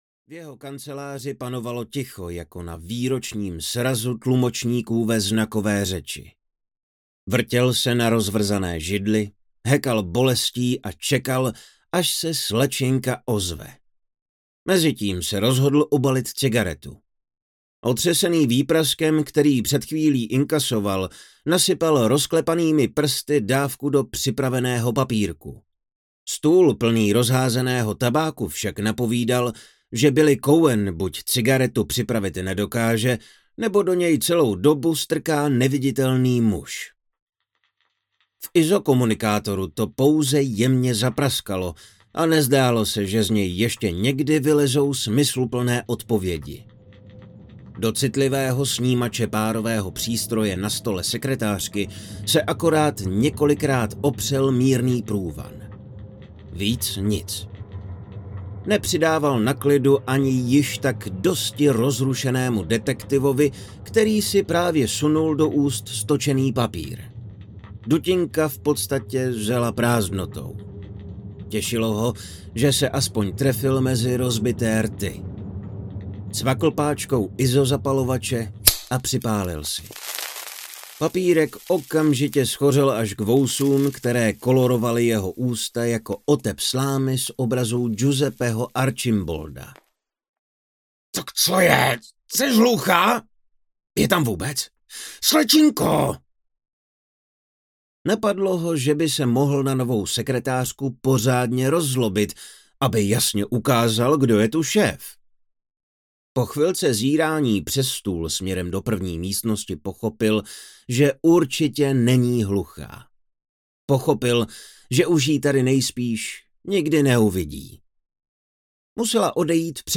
Do smrti daleko audiokniha
Ukázka z knihy